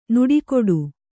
♪ nuḍI koḍu